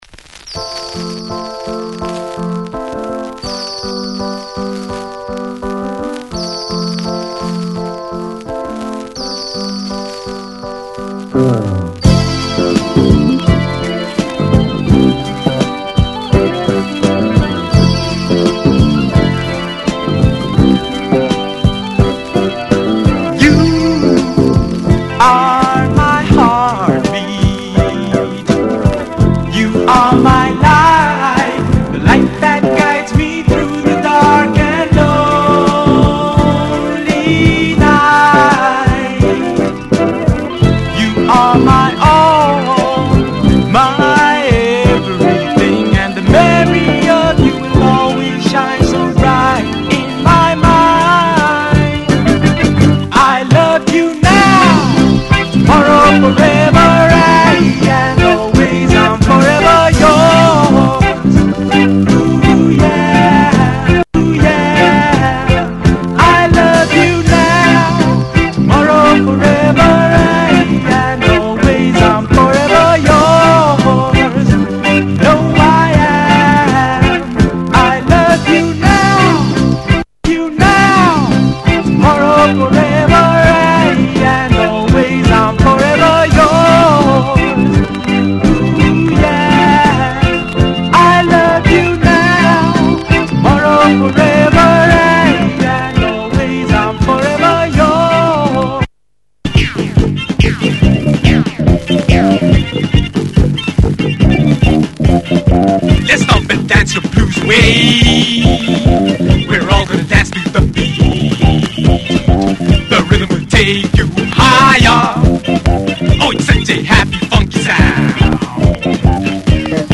laydback afro-disco balad, B-side is a bass romp.